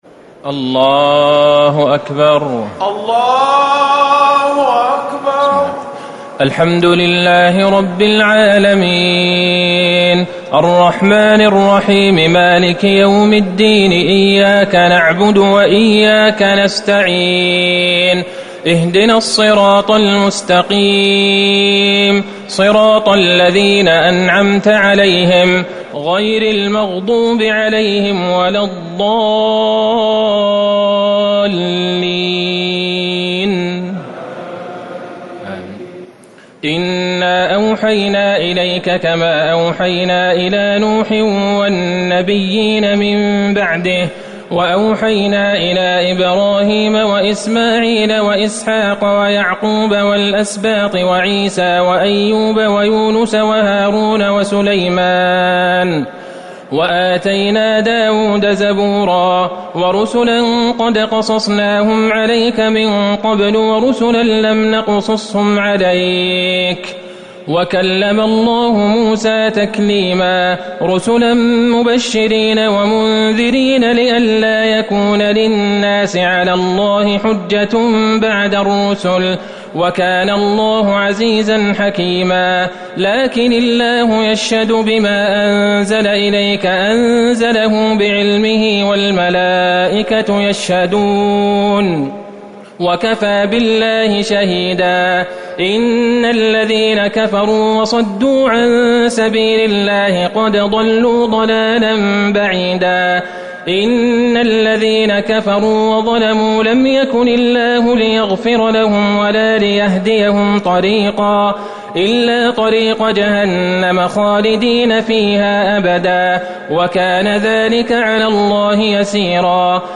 ليلة ٥ رمضان ١٤٤٠ من سورة النساء ١٦٣ - المائدة ٤٠ > تراويح الحرم النبوي عام 1440 🕌 > التراويح - تلاوات الحرمين